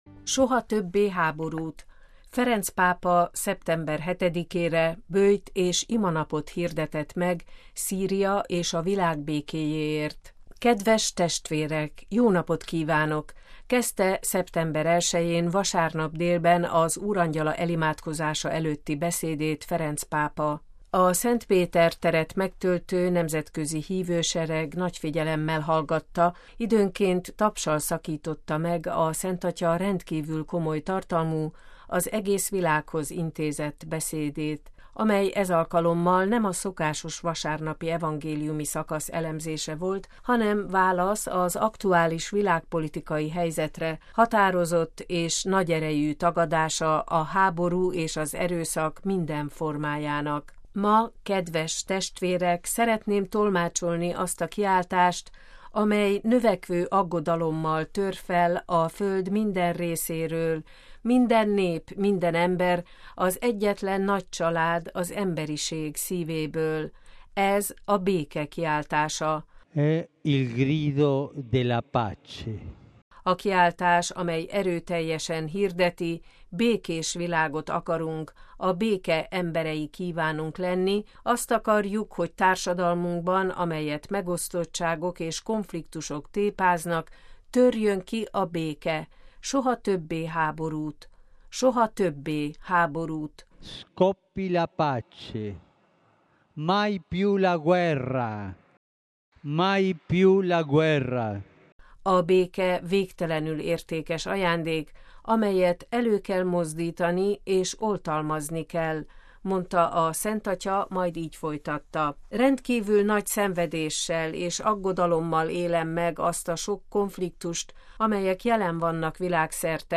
A Szent Péter teret megtöltő nemzetközi hívősereg nagy figyelemmel hallgatta, időnként tapssal szakította meg a Szentatya rendkívül komoly tartalmú, az egész világhoz intézett beszédét, amely ez alkalommal nem a szokásos vasárnapi evangéliumi szakasz elemzése volt, hanem válasz az aktuális világpolitikai helyzetre, határozott és nagy erejű tagadása a háború és az erőszak minden formájának.
Ferenc pápa végül felszólította a híveket, hogy ismételjék vele együtt: Mária, Béke Királynője, könyörögj értünk!